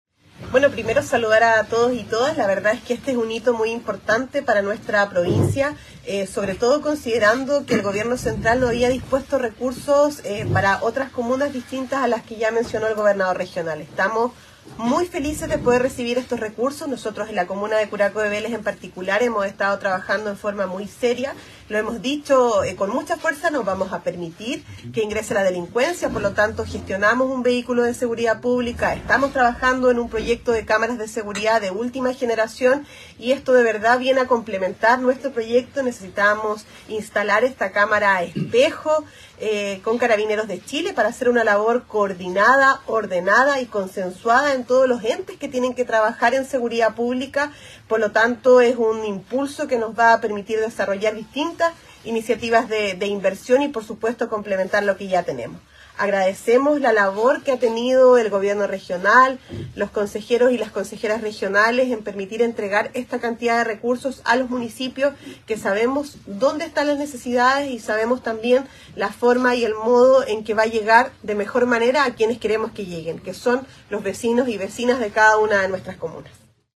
Al respecto, la alcaldesa Yáñez manifestó que estos fondos vienen a complementar dos importantes proyectos para la comuna: la pronta llegada de un vehículo de patrullaje preventivo y un moderno sistema de cámaras de televigilancia que lleva más de un 70 % de ejecución: